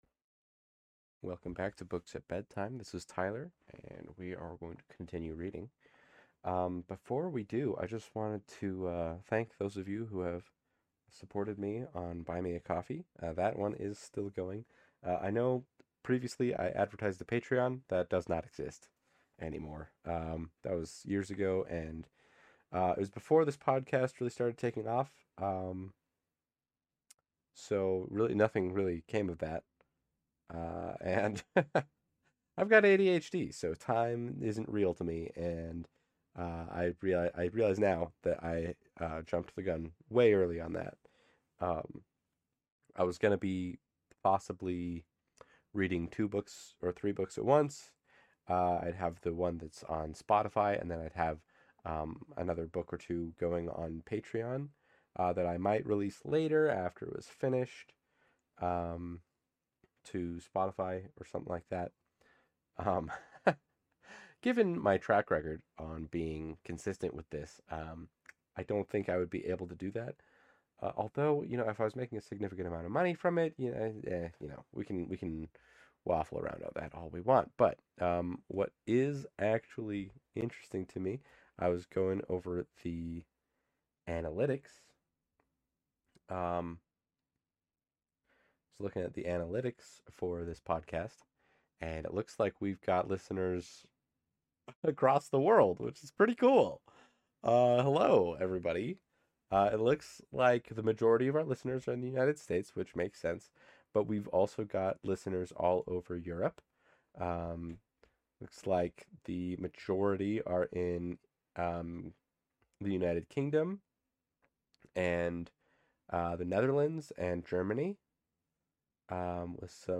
I'd like to offer people a chance to listen to stories as they might hear them read by a parent or older sibling, complete with laughter, commentary, and all the other